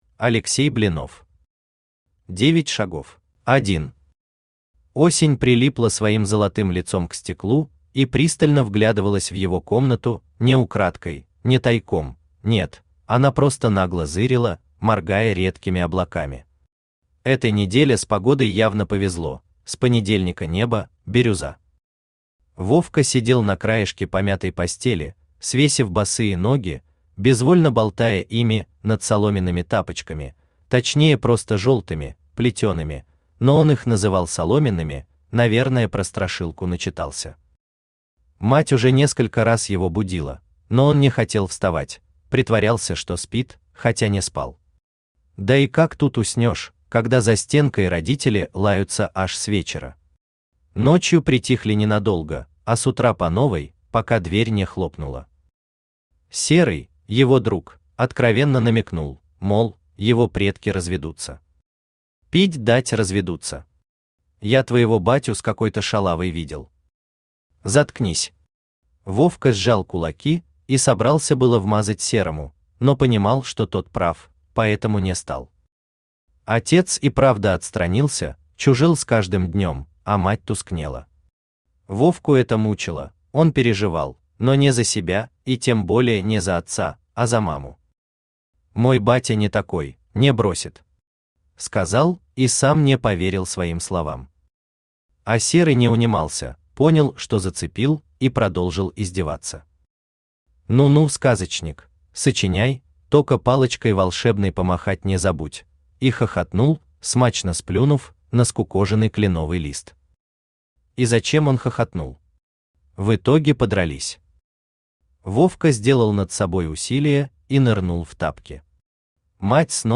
Аудиокнига Девять шагов | Библиотека аудиокниг
Aудиокнига Девять шагов Автор Алексей Викторович Блинов Читает аудиокнигу Авточтец ЛитРес.